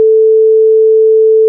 In het volgende luistervoorbeeld horen we een zuivere toon van 440 Hz.
toon van 440 Hz
440Hz.wav